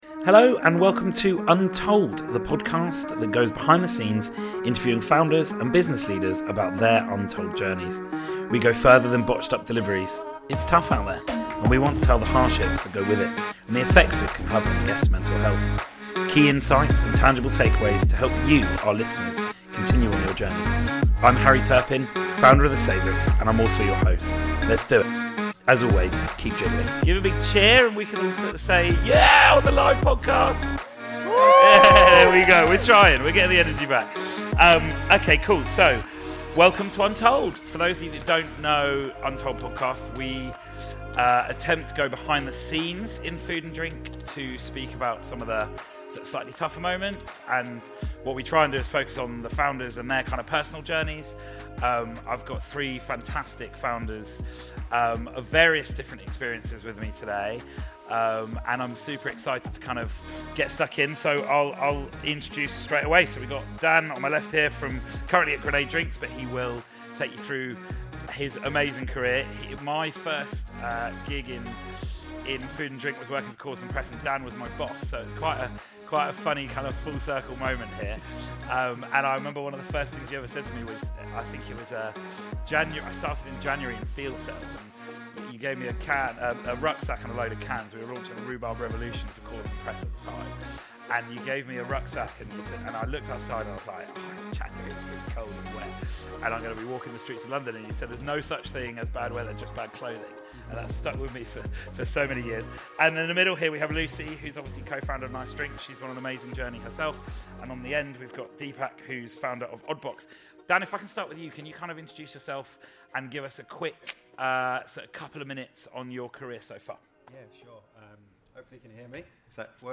Live episode recorded from Bread and Jam 2023.